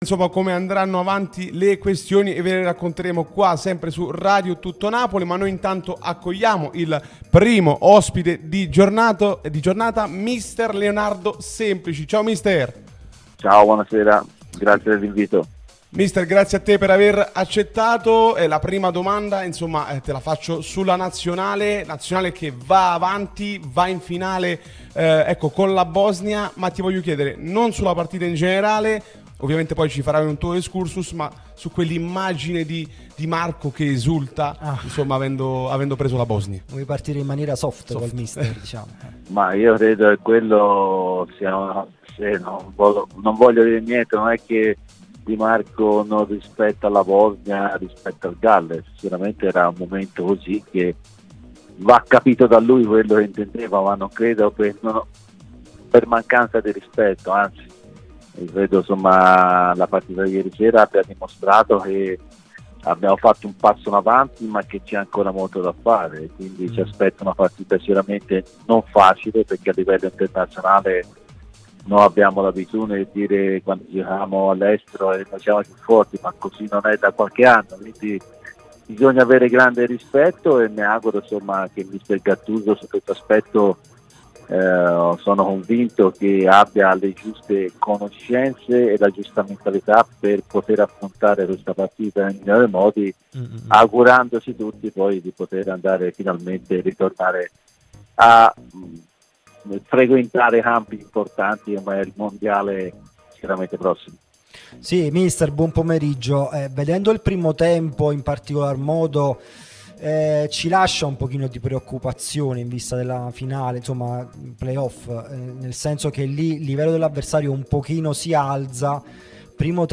Leonardo Semplici è stato nostro ospite su Radio Tutto Napoli, l'unica radio tutta azzurra e sempre live, che puoi seguire sulle app gratuite (per Iphone o per Android, Android Tv ed LG), in auto col DAB o qui sul sito anche in video.